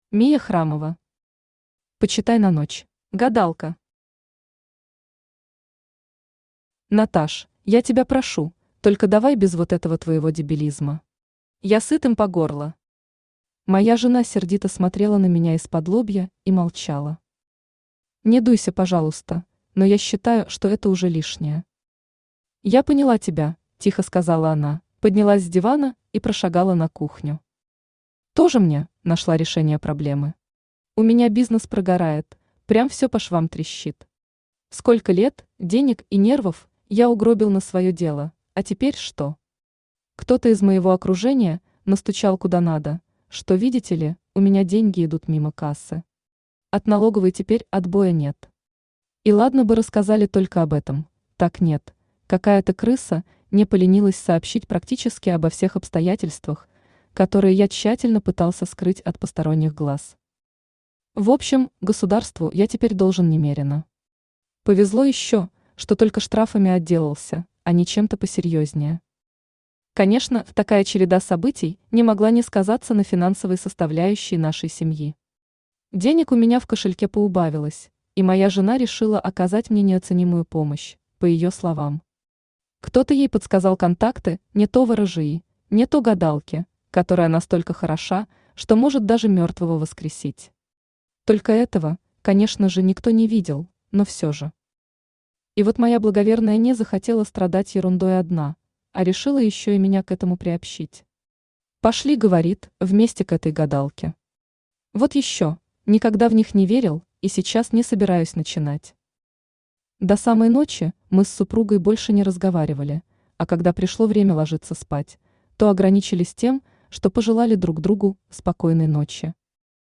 Аудиокнига Почитай на ночь | Библиотека аудиокниг
Aудиокнига Почитай на ночь Автор Миа Храмова Читает аудиокнигу Авточтец ЛитРес.